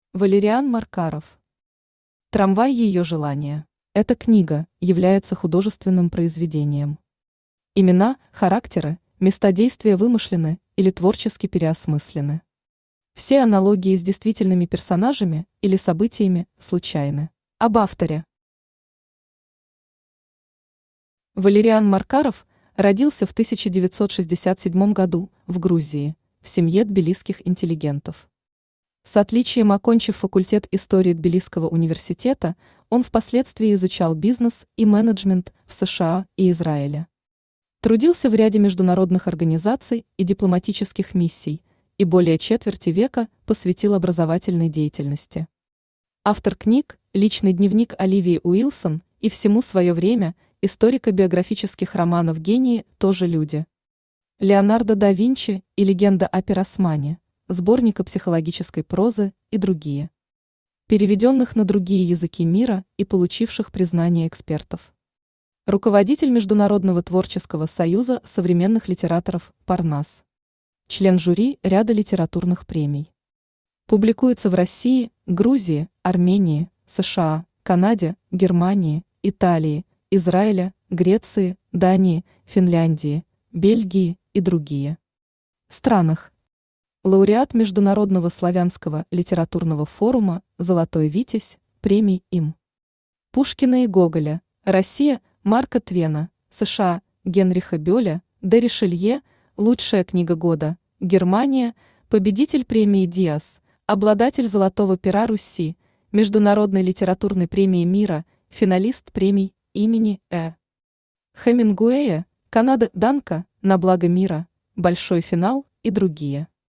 Аудиокнига Трамвай её желания | Библиотека аудиокниг
Aудиокнига Трамвай её желания Автор Валериан Маркаров Читает аудиокнигу Авточтец ЛитРес.